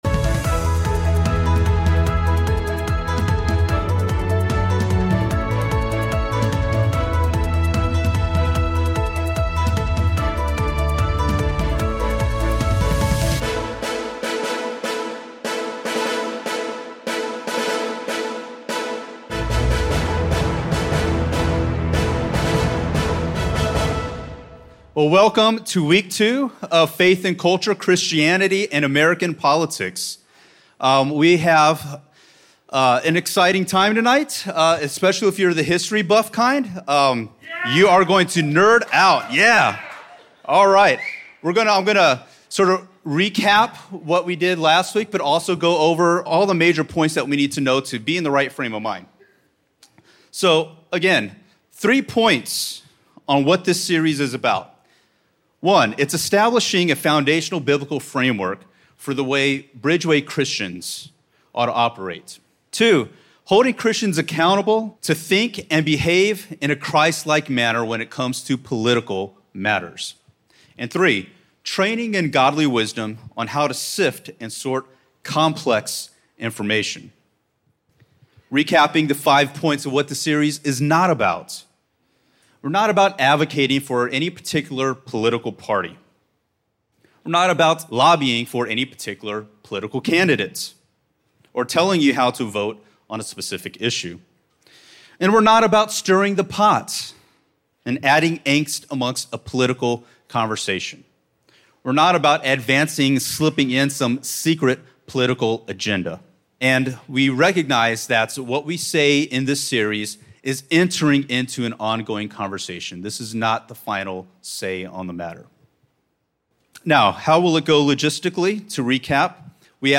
{In April 2024, Bridgeway hosted Faith & Culture: Christianity and American Politics, a four-week Sunday night series aimed at helping Christians understand the political world and approach politics in a more Christlike manner.